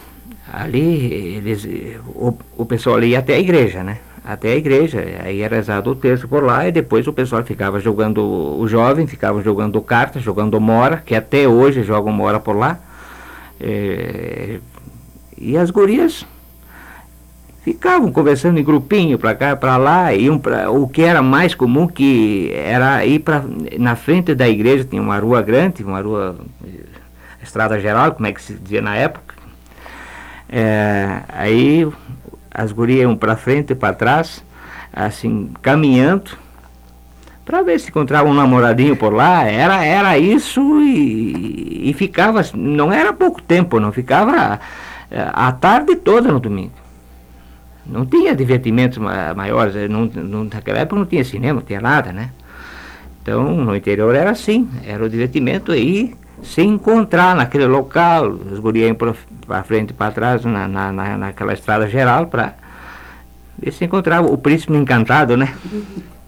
Trecho de áudio da entrevista